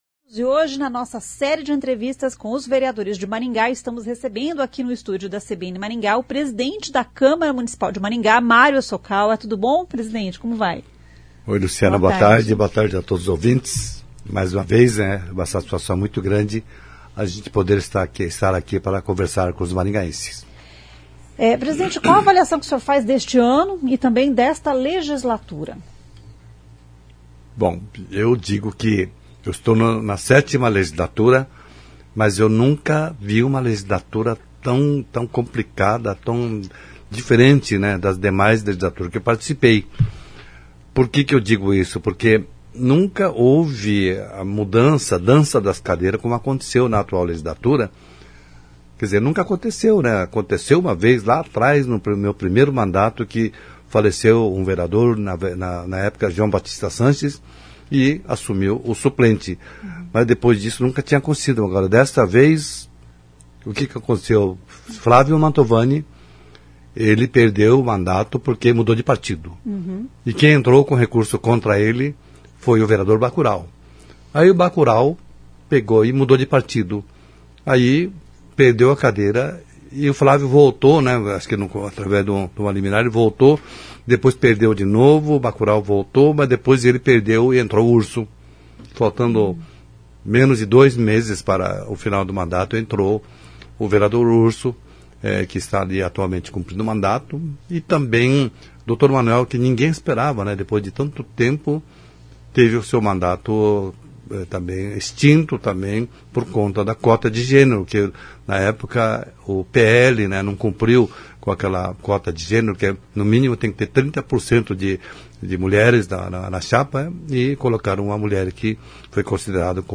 O presidente da Câmara de Maringá, vereador Mário Hossokawa é entrevistado nesta edição da Série Vereadores.